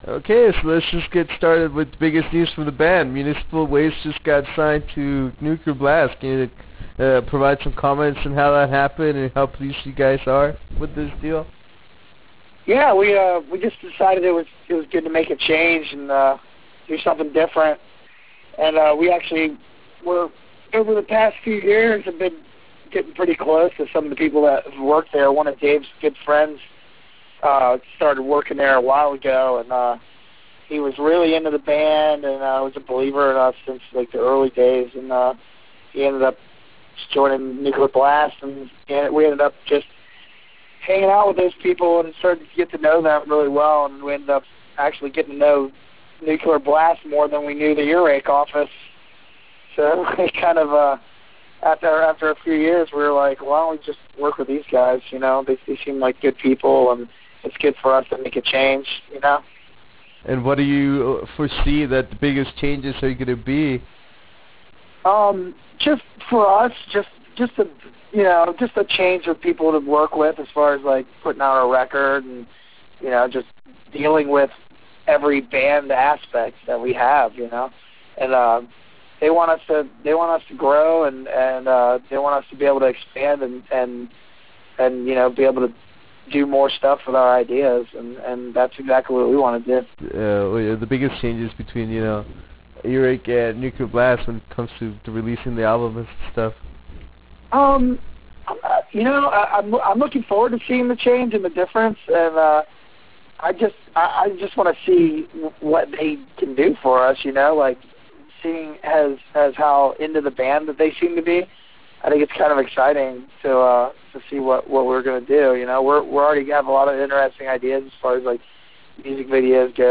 Interview with Tony Foresta of Municipal Waste
Weeks after their signing to Nuclear Blast, Municipal Waste vocalist Tony Foresta talks to us about the label change and what is in store for the fans of the band in the future. We also talk about the band’s world domination plans and the goal of playing with on the ‘Big Five’ tour.
Interview with Municipal Waste - Tony Foresta.wav